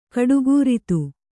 ♪ kaḍugūritu